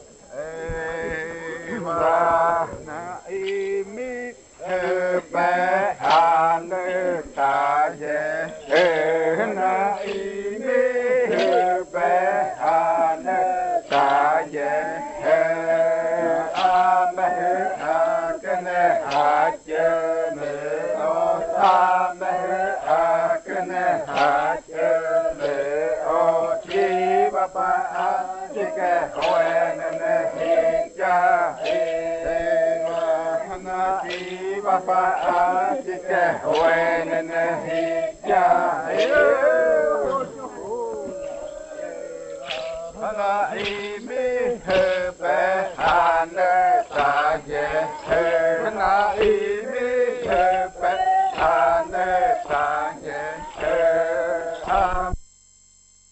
Baile de nombramiento.
Puerto Remanso del Tigre, departamento de Amazonas, Colombia
en casete y en el año de 1990